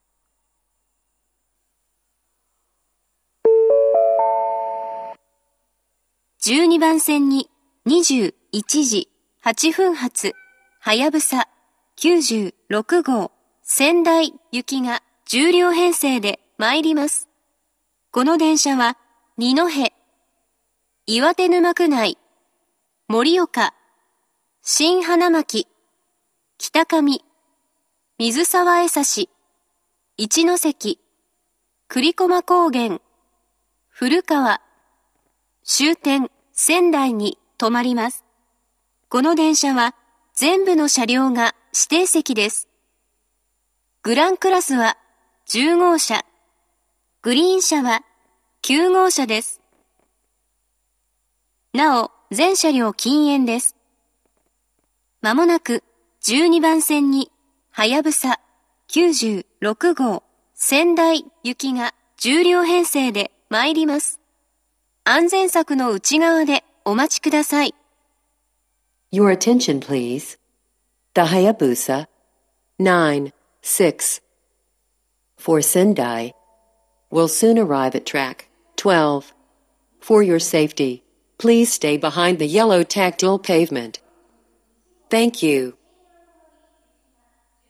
１２番線接近放送